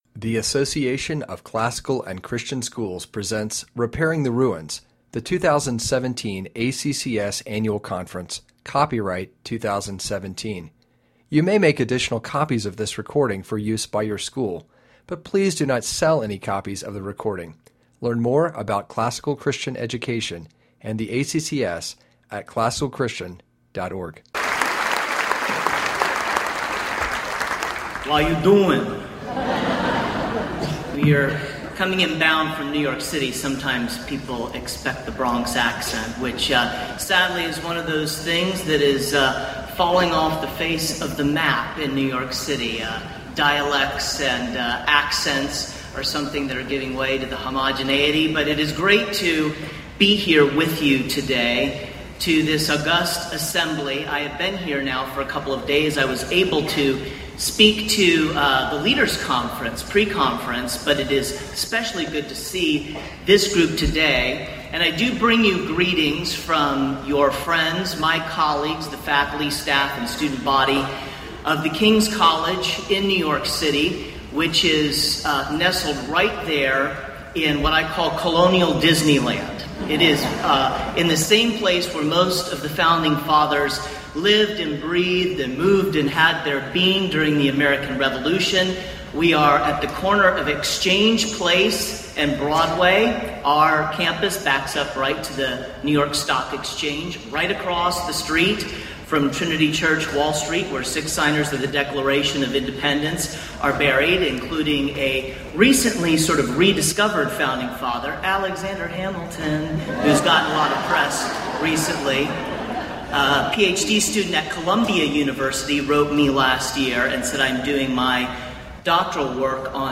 2017 Plenary Talk | 0:48:38 | Culture & Faith
The Association of Classical & Christian Schools presents Repairing the Ruins, the ACCS annual conference, copyright ACCS.